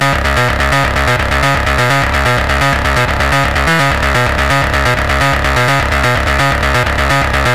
Grainulator C# 127.wav